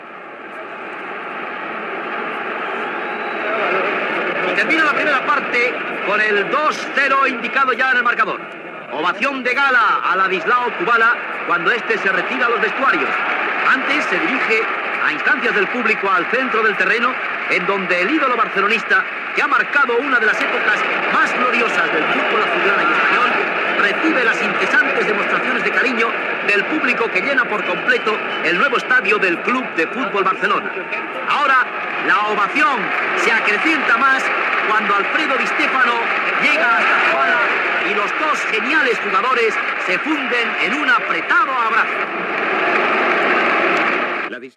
Recreació de la narració del final de la primera part del partit amb una abraçada entre Kubala i Di Stefano.
Esportiu
Segon fragment extret del programa "Memòries de la ràdio" de Ràdio 4 emès el 29 de març del 2011.